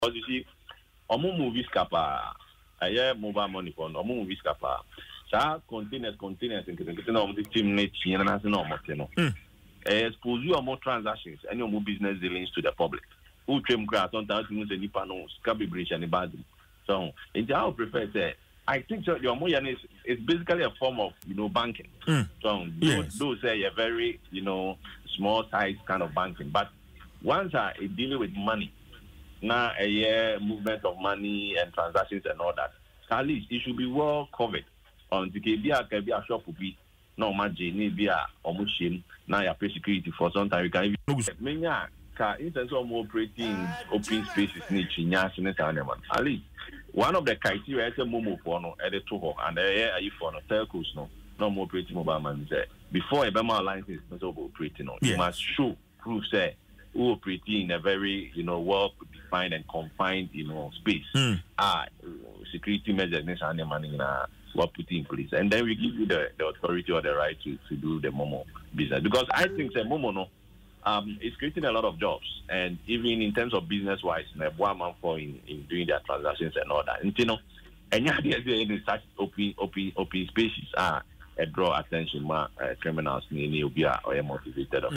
In an interview on Adom FM’s Dwaso Nsem